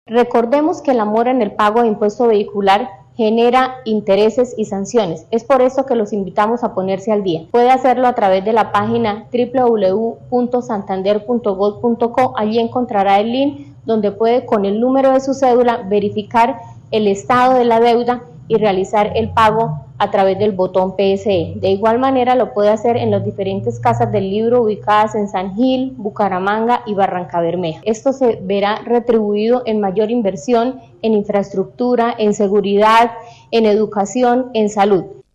Diana María Durán, secretaria de Hacienda de Santander